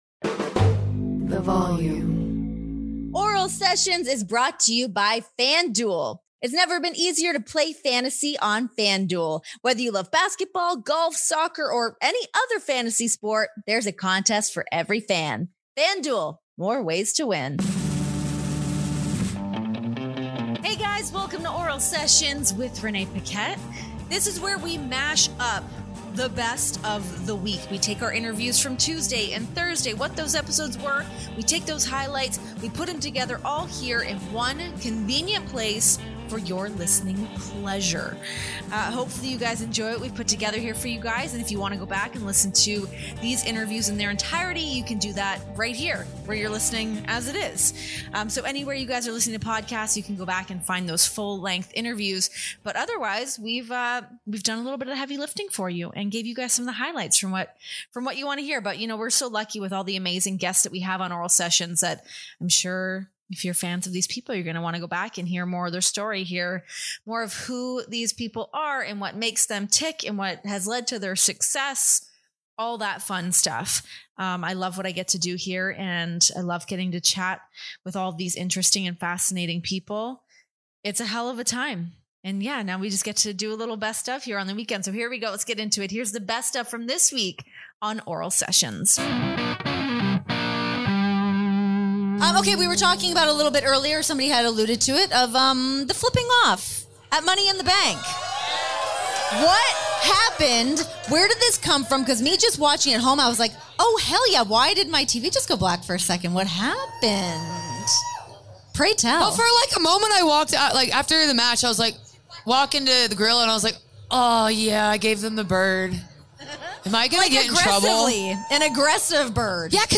Relive the best of Oral Sessions this week, featuring highlights from our interviews with Charlotte Flair and Eva Marie.